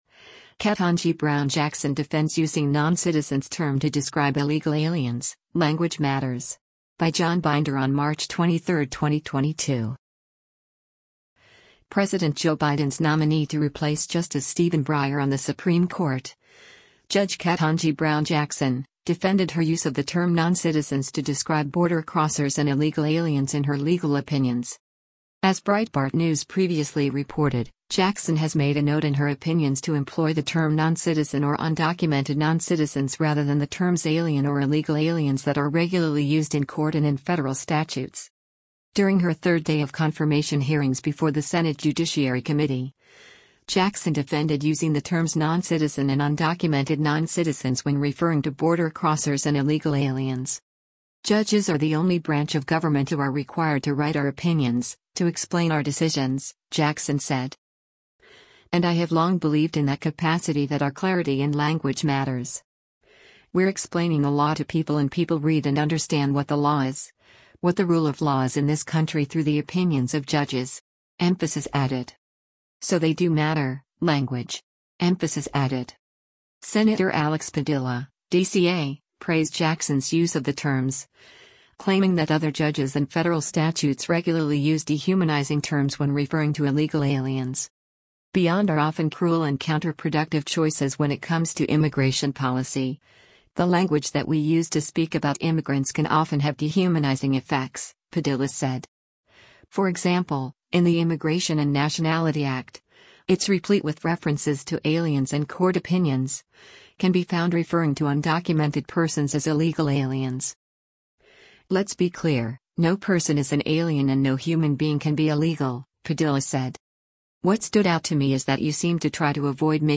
During her third day of confirmation hearings before the Senate Judiciary Committee, Jackson defended using the terms “noncitizen” and “undocumented non-citizens” when referring to border crossers and illegal aliens.